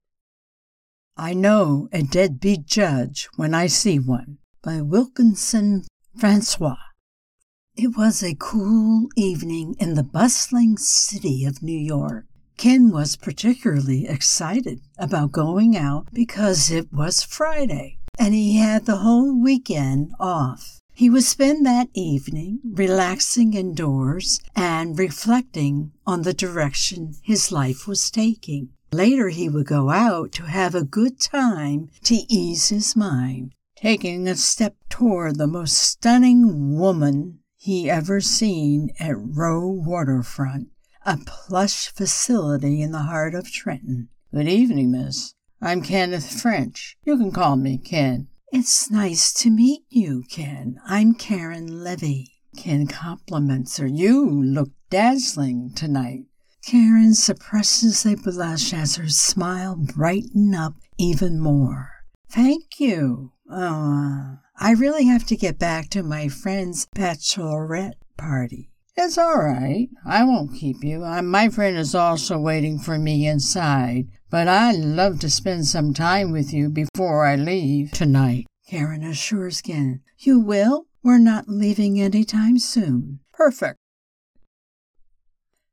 Narrations are recorded with a home studio-quality MSB Mic, providing consistent and reliable performance.